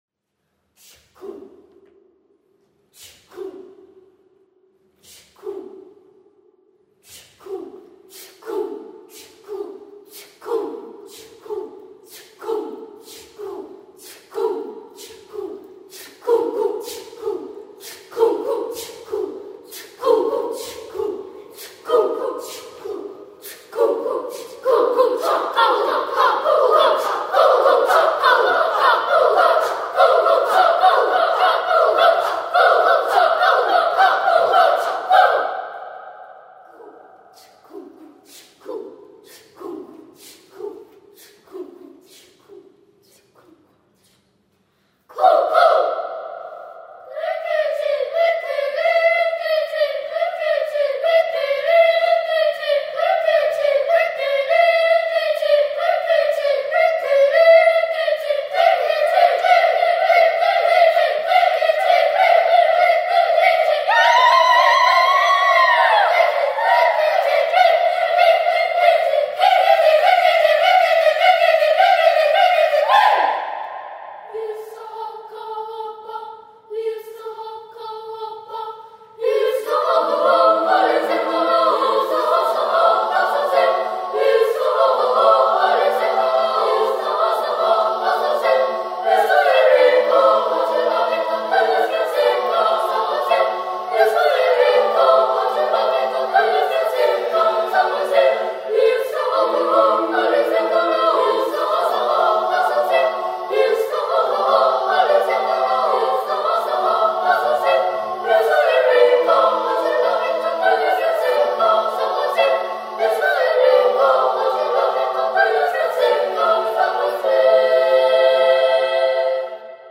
SATB div.